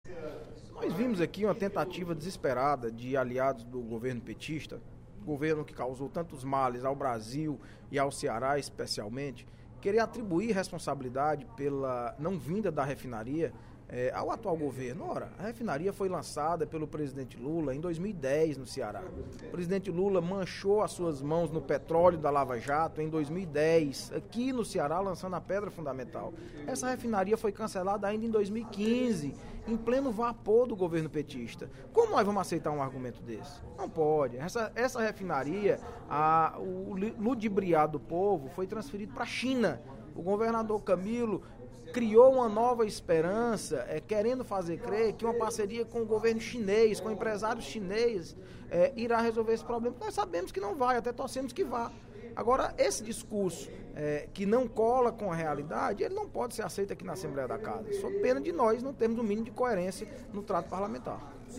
O deputado Audic Mota (PMDB) criticou, durante o primeiro expediente da sessão plenária desta sexta-feira (08/07), o discurso de parlamentares governistas que se manifestaram contra a proposta de criação de um plano de saúde “popular”, anunciada ontem pelo Ministro da Saúde, Ricardo Barros.